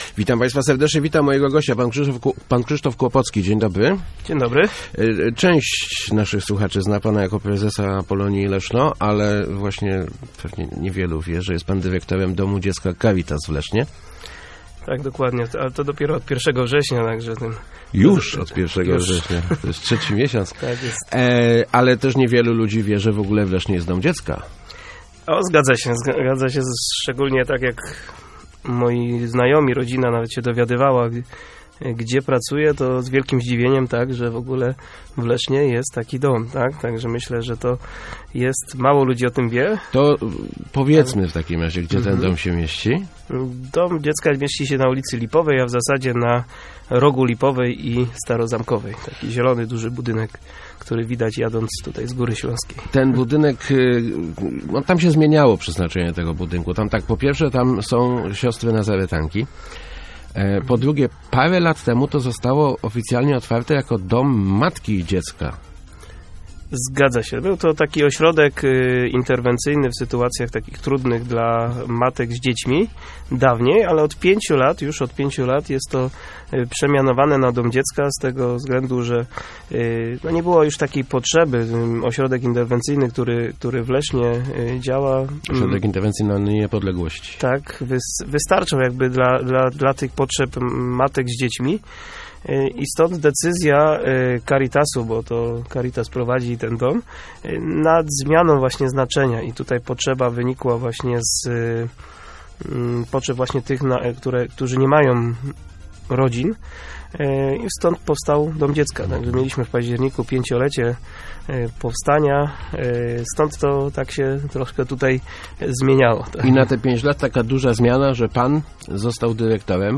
-To jednyna taka placówka w diecezji - mówił w Rozmowach Elki